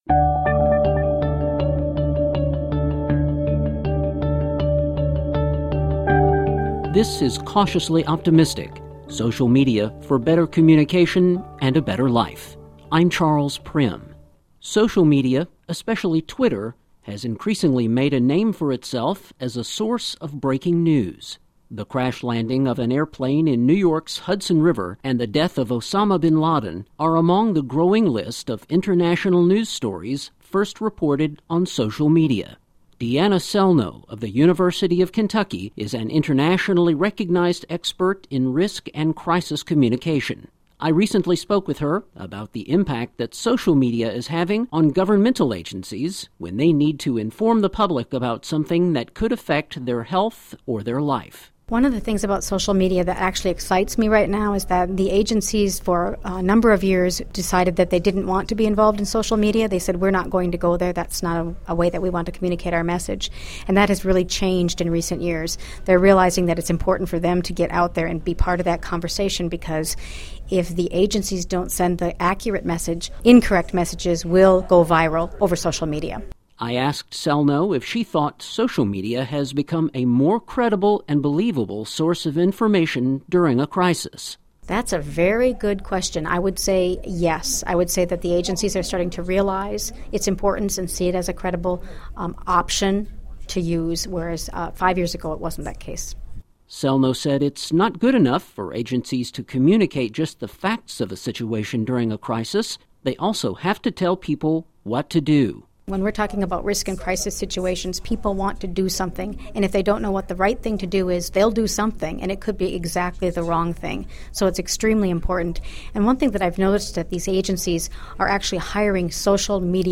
Audio Podcast: Social Media and Crisis Communication